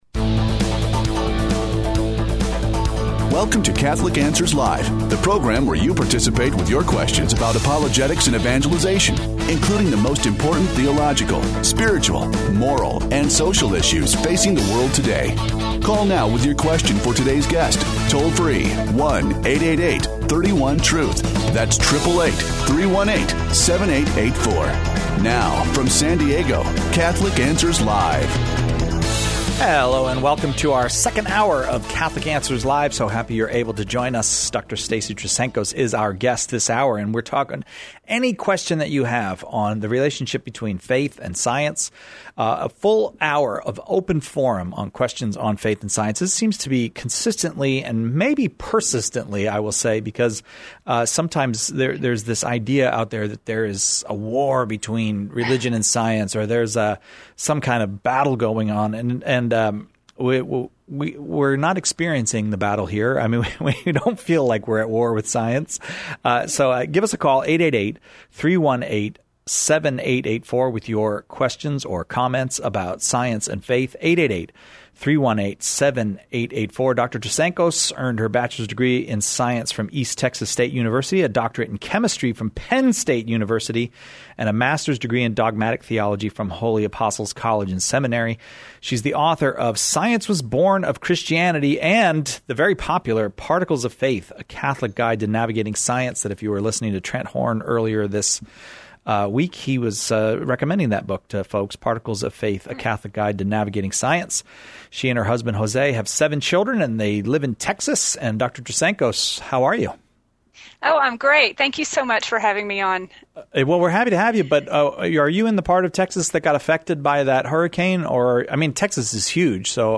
A full hour of open forum on faith and science lets callers ask about evolution and creation, the possibility of finding God through science, and even the possi...